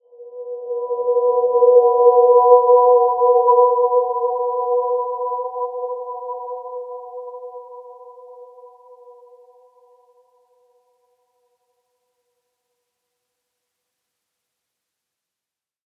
Dreamy-Fifths-B4-p.wav